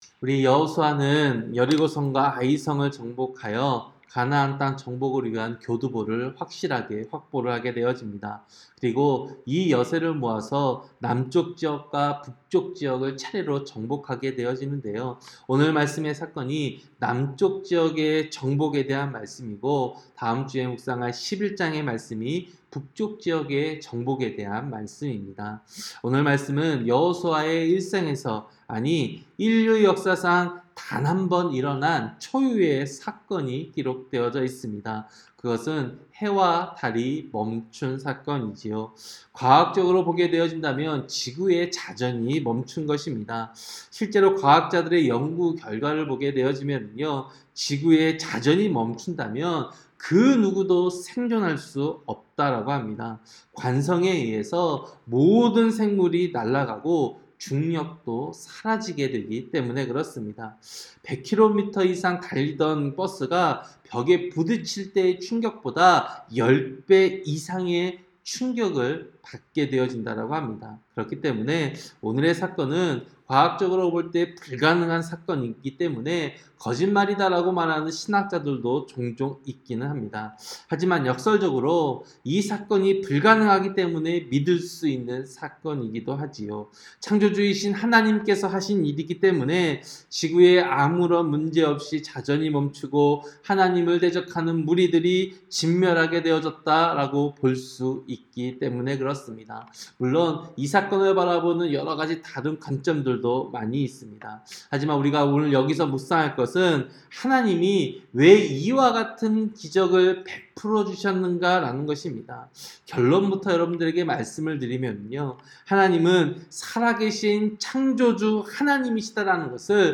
새벽기도-여호수아 10장